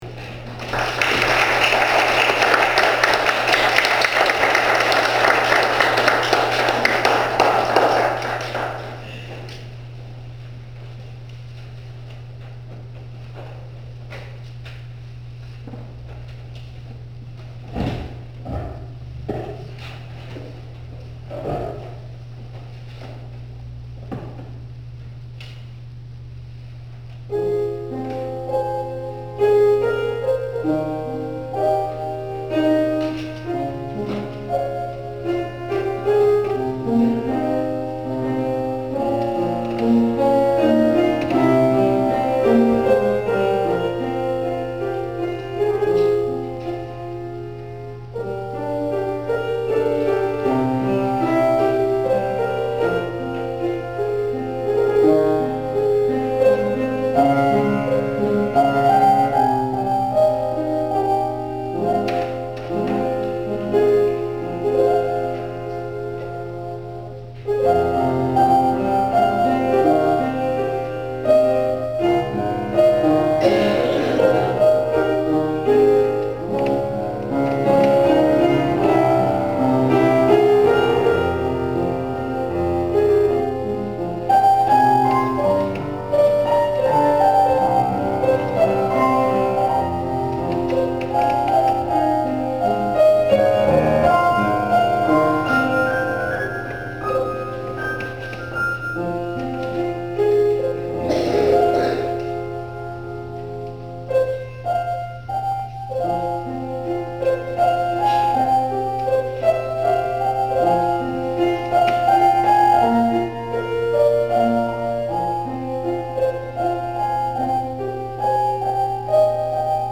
It is now over 8 years since I did my one solo concert at a church in a Congregational Church in Wisconsin. I was fortunate enough to have a Kodak camera with decent audio, and filmed the whole thing.
Title is Meditation. I don’t sing on this track, but it musically flows straight into the second track, where I do sing — but that is getting ahead of myself.
I had a good pianist — she worked well with me, but she wasn’t as flexible as my favorite pianist.
For the concert 8 years ago, the pianist did a nice job on Meditation, but I would have upped the tempo, and the push-pull of the rubato, more than she did.